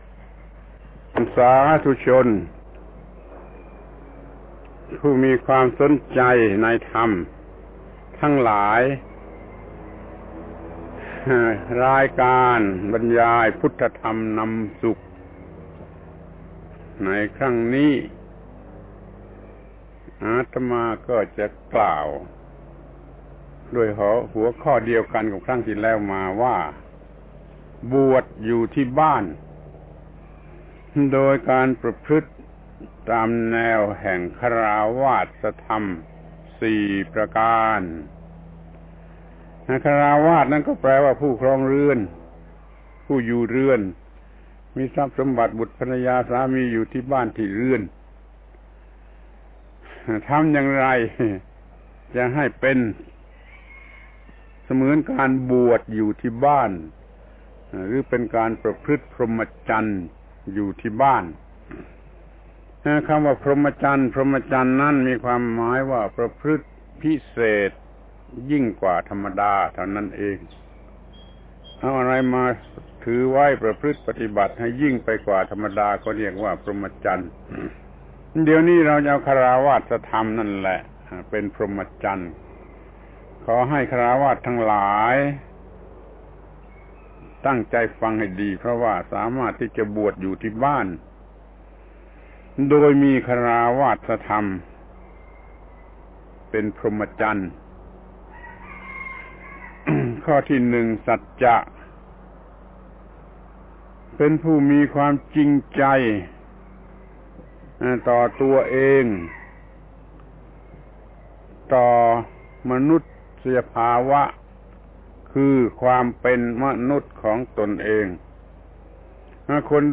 พระธรรมโกศาจารย์ (พุทธทาสภิกขุ) - ปาฐกถาธรรมทางโทรทัศน์รายการพุทธธรรมนำสุข ชุดบวชอยู่ที่บ้าน (มี ๔ ตอน) ครั้ง ๖๙ บวชอยู่ที่บ้านโดยประพฤติฆราวาสธรรมสี่ประการ